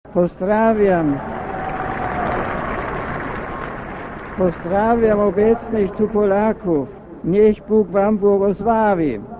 Mówi Benedykt XVI
papiez-po_polsku-benedykt.mp3